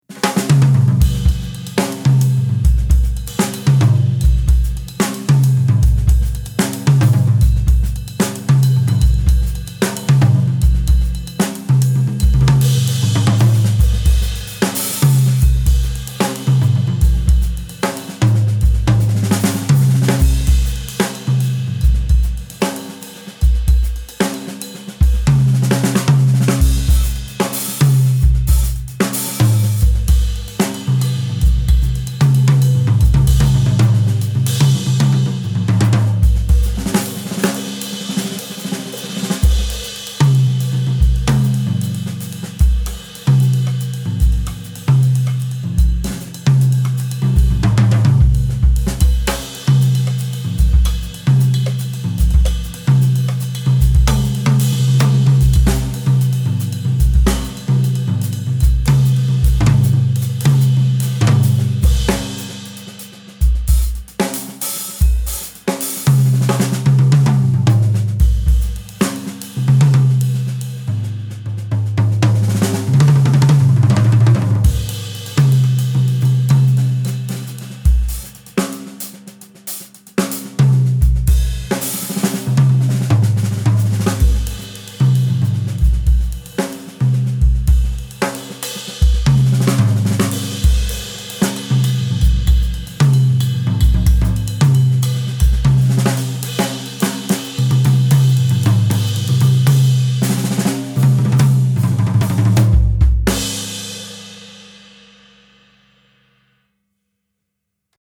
We were sent a four-piece bop-style kit, featuring 8-ply all-cherry HVLT (Horizontal/Vertical Low Timbre) shells in satin oil finish with nickel hardware.
Here’s how the kit sounds when tuned low.
DW-Cherry-kit-low-tuning.mp3